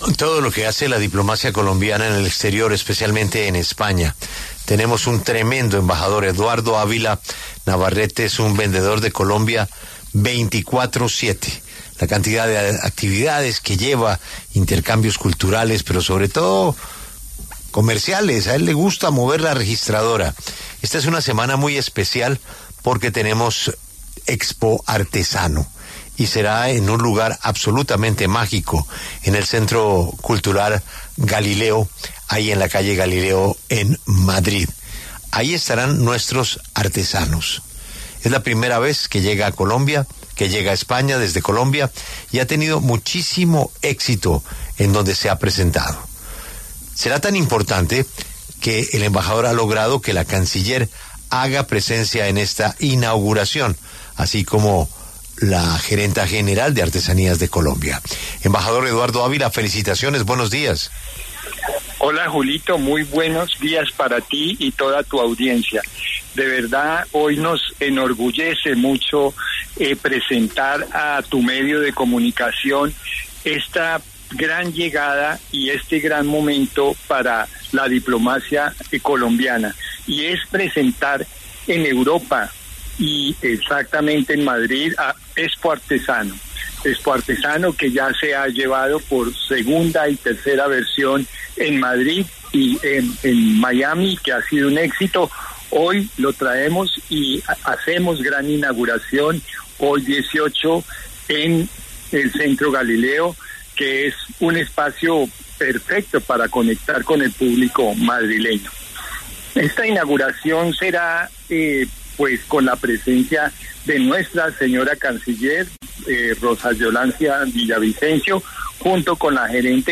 Este martes, 18 de noviembre, habló en los micrófonos de La W, con Julio Sánchez Cristo, el embajador de Colombia en España, Eduardo Ávila Navarrete, quien se refirió al Expoartesano Madrid 2025, un espacio que celebra la riqueza artesanal y artística de Colombia, y que llega por primera vez al país ibérico.